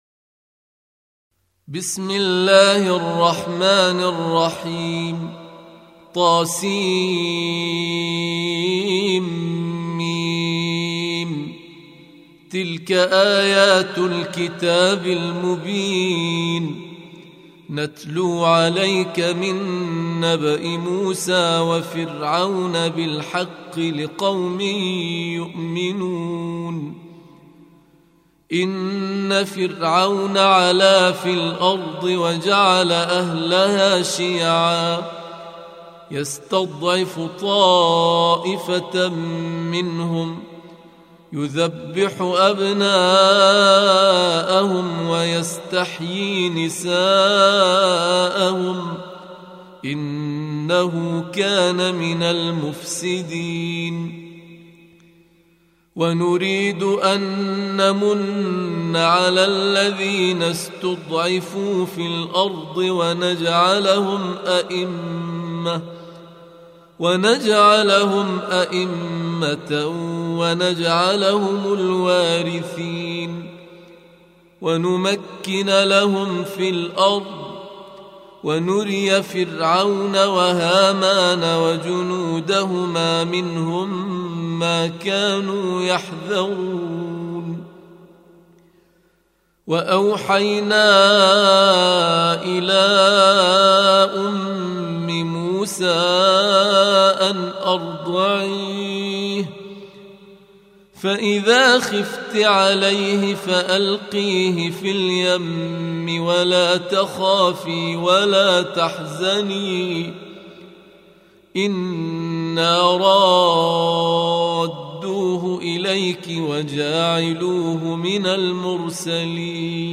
Audio Quran Tarteel Recitation